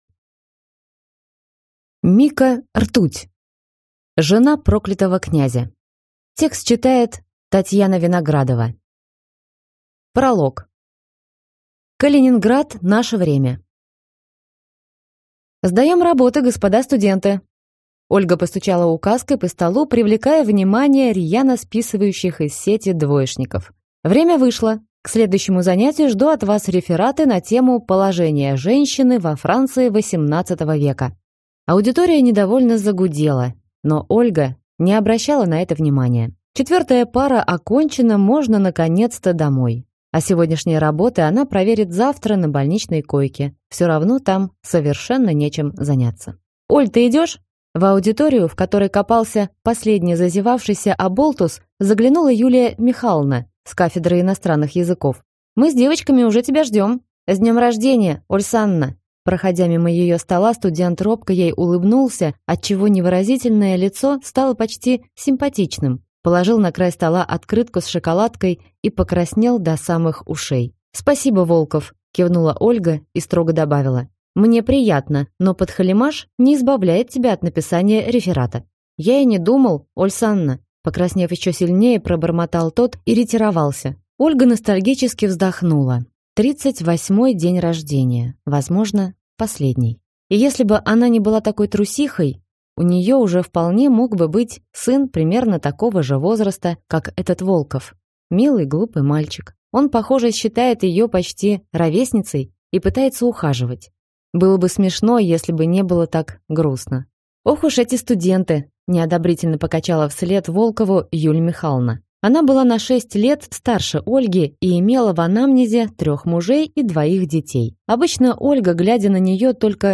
Aудиокнига Жена проклятого князя